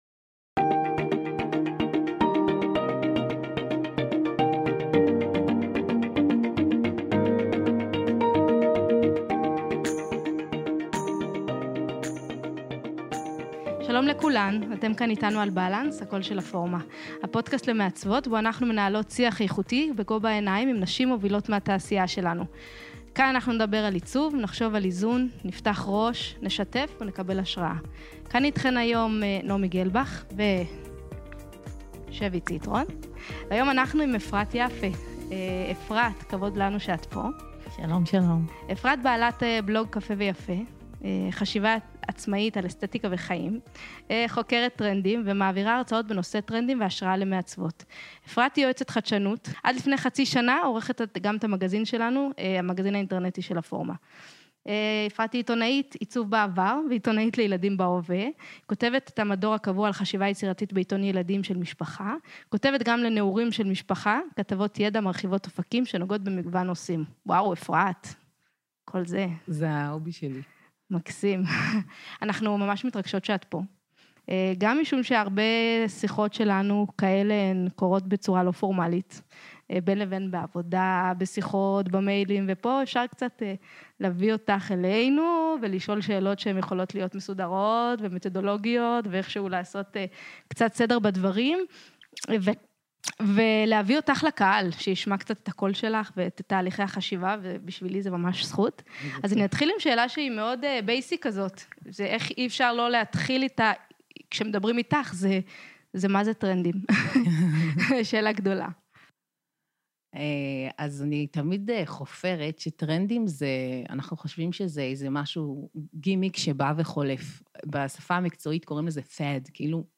שיחה טובה ומעוררת את הלב והראש.